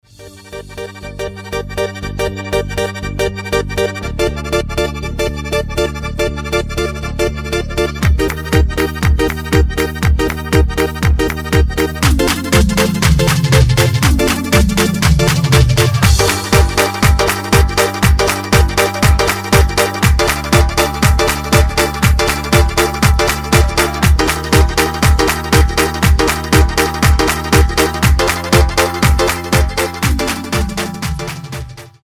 танцевальные , инструментальные , без слов